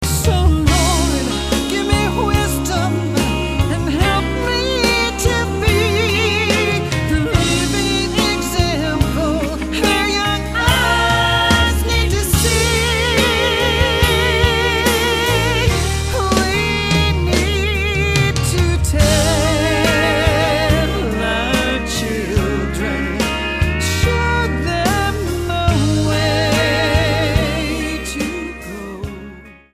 STYLE: Country
has a strong singing voice
the backing is pleasant modern country